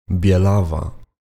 Bielawa [bʲɛˈlava]
Pl-Bielawa.ogg.mp3